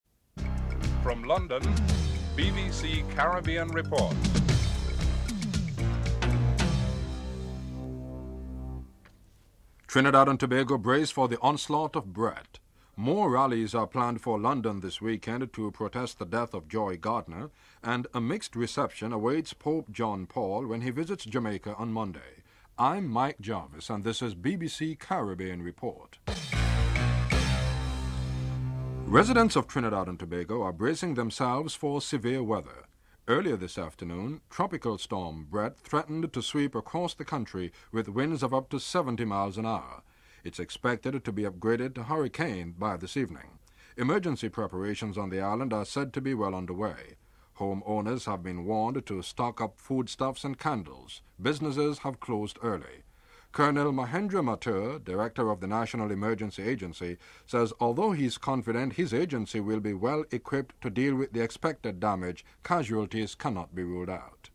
1. Headlines (00:00-00:37)
Interview with Colonel Mahendra Mathur, Director, National Emergency Management Agency.
Speaking on Caribbean Report, Mr. Esquivel said he wants to have a clear indication from the government of Guatemala on its relations with Belize.